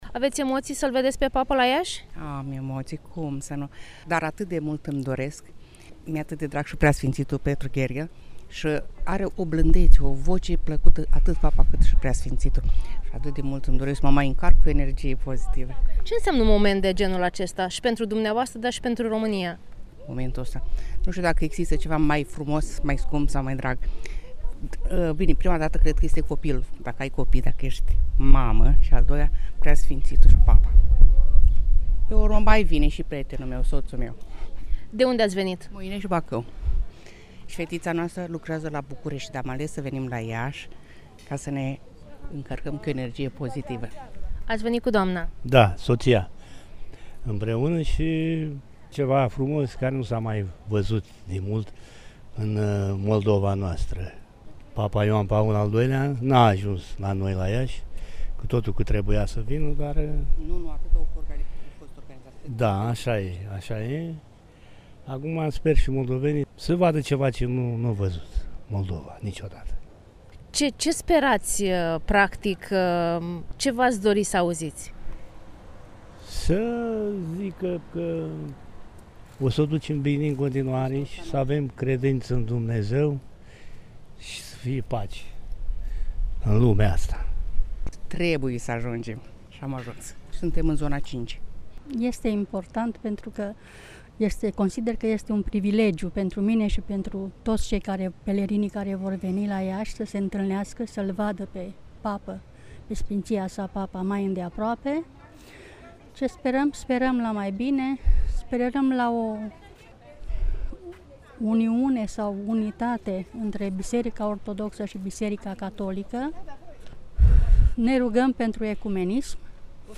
Colega noastră a stat de vorbă şi cu câţiva pelerini veniţi să îl întâmpine pe Suveranul Pontif:
1-iunie-inserturi-pelerini.mp3